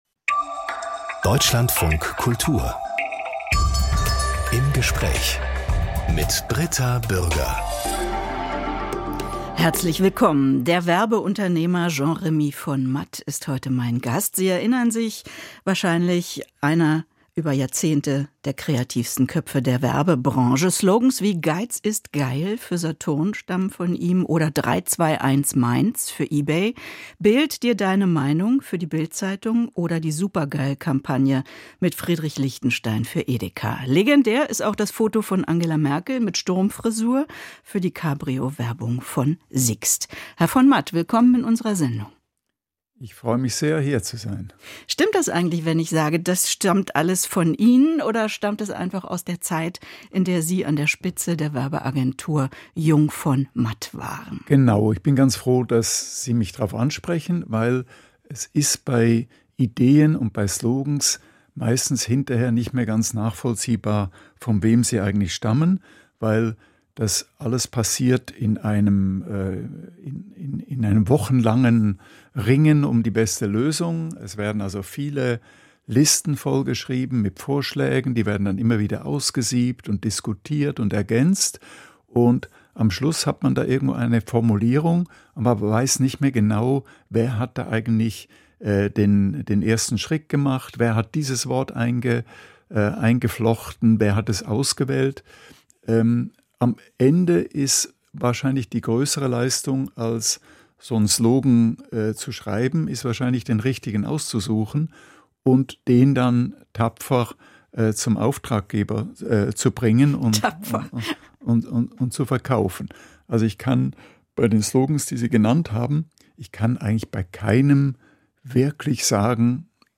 Aus dem Podcast Im Gespräch Podcast abonnieren Podcast hören Podcast Im Gespräch Eine ganze Stunde widmen wir einer Person.